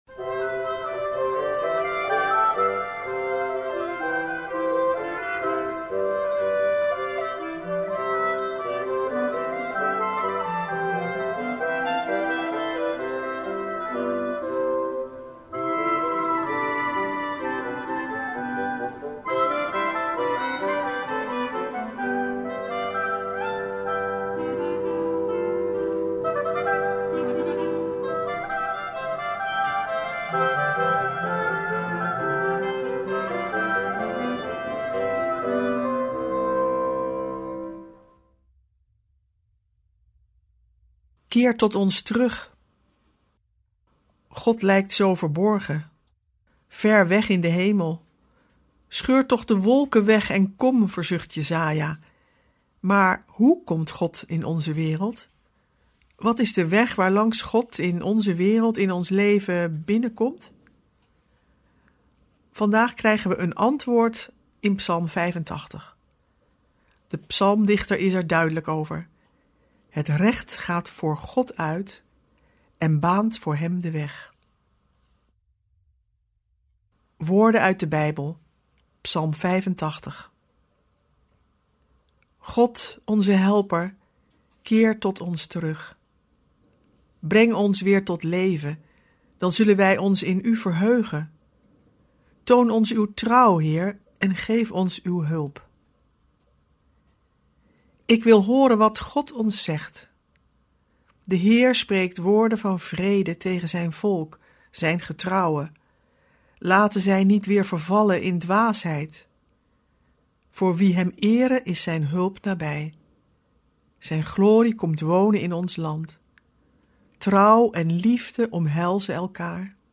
Ook dit jaar komen klassieke Bijbelteksten aan de orde, teksten die al eeuwen in de kerk juist in deze weken van Advent zijn gelezen. We verbinden ze met onze tijd en we luisteren naar prachtige muziek en poëzie.